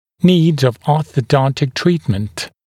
[niːd fə ˌɔːθə’dɔntɪk ‘triːtmənt][ни:д фо ˌо:сэ’донтик ‘три:тмэнт]потребность в ортодонтическом лечении